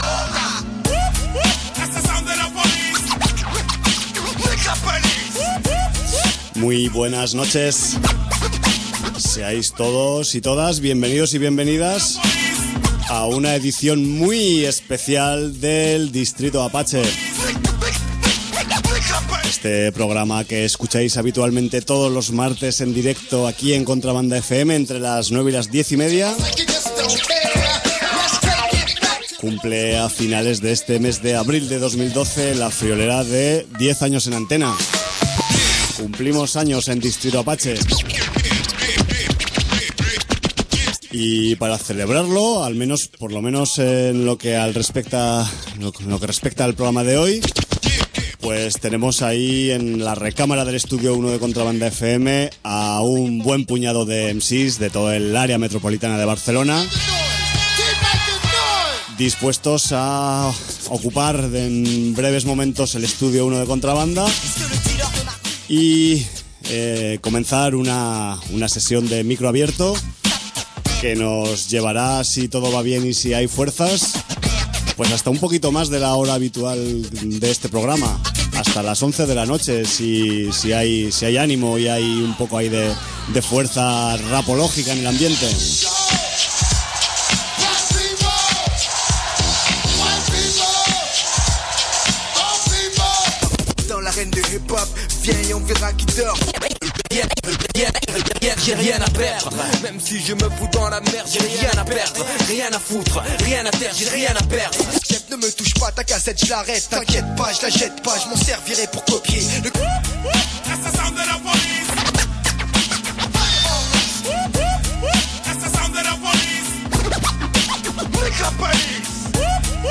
Programa especial 10º aniversario con 2 horas de micro abierto, y 20 MCs rimando en directo